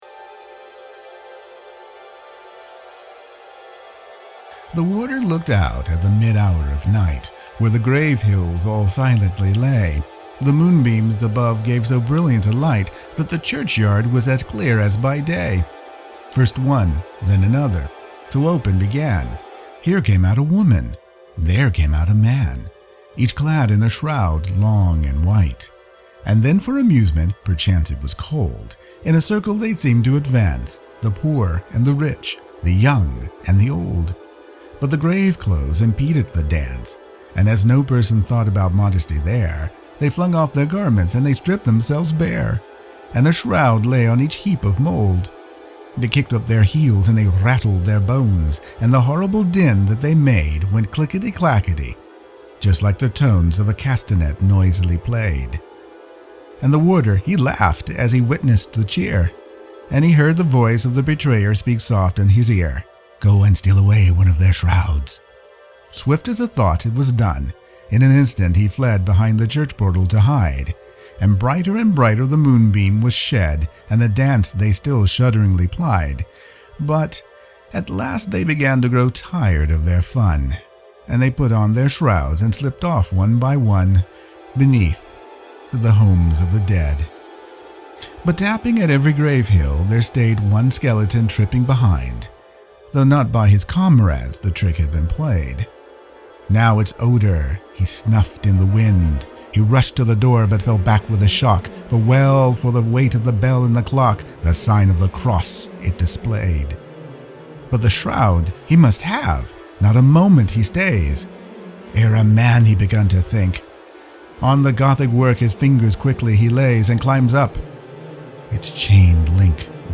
Skeleton Dance – Music and Reading